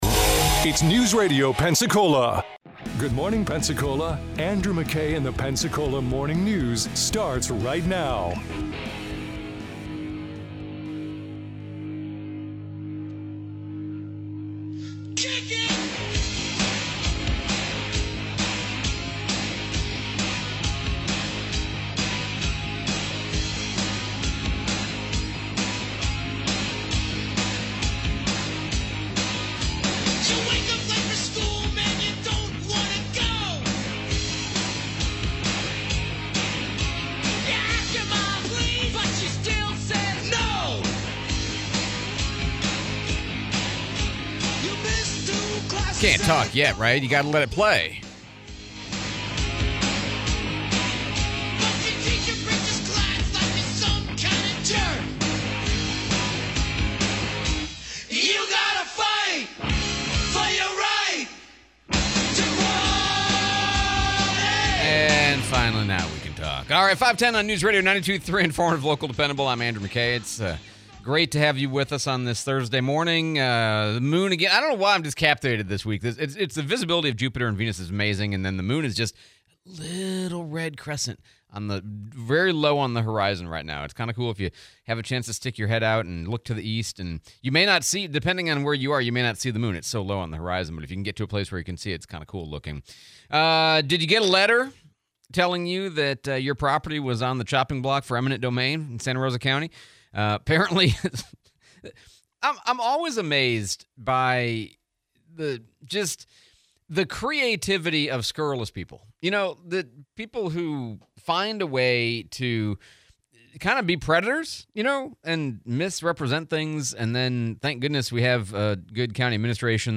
Santa Rosa County eminent domain, interview with Mayor Reeves